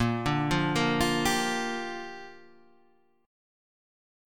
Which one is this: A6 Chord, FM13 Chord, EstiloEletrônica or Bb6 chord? Bb6 chord